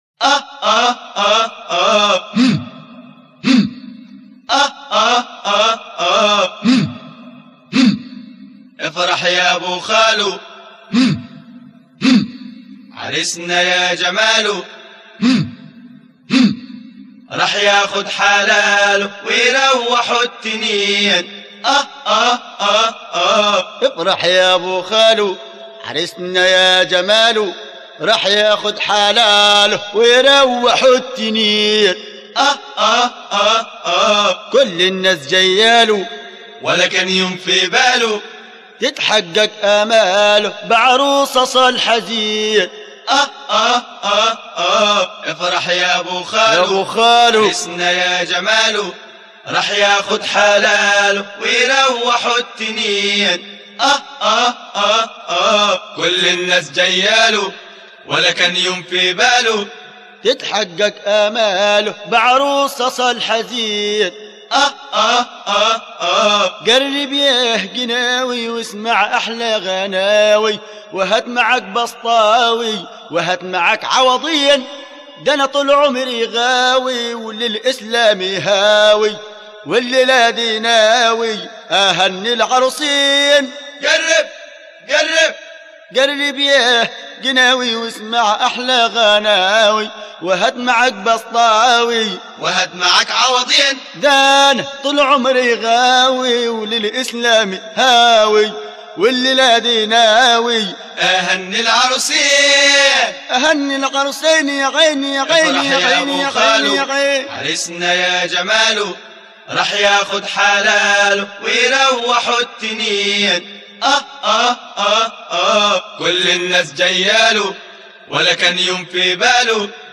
أناشيد ونغمات